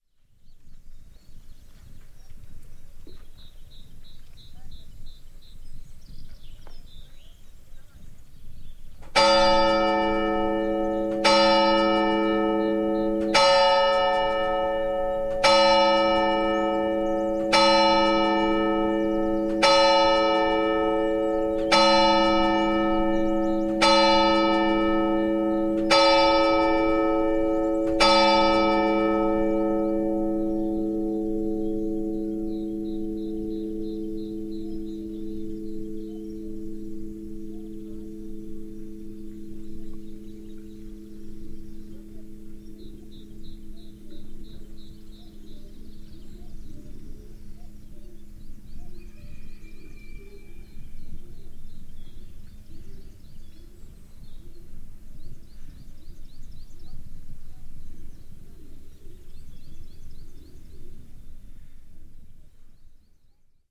Toque das 10 horas da manhã do sino da igreja de S. Martinho das Moitas captado a uma distância curta, sendo possível ouvir uma reverberação de cerca de 15 segundos após a última badalada. Gravado com Fostex FR-2LE e um microfone Audio-Technica AT-822.
Tipo de Prática: Paisagem Sonora Rural
S.-Martinho-das-Moitas-Toque-do-sino.mp3